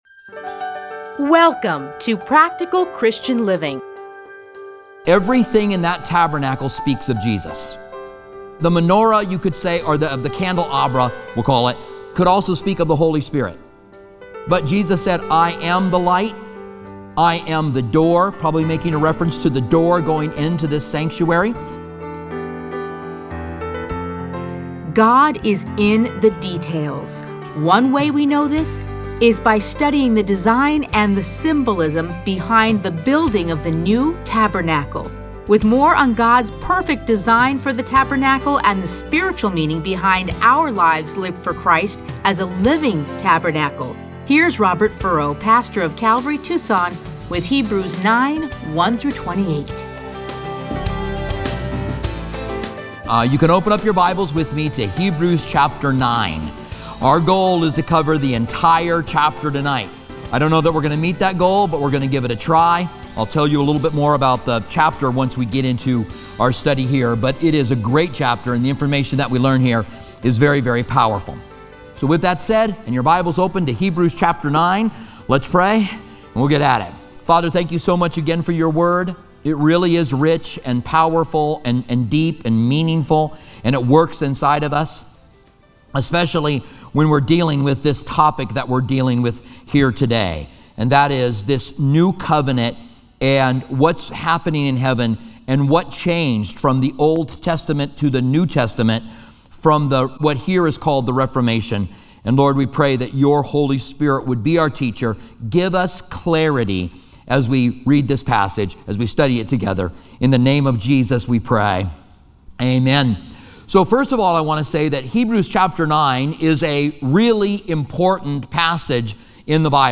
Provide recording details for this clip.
edited into 30-minute radio programs titled Practical Christian Living. Listen to a teaching from Hebrews 9:1-28.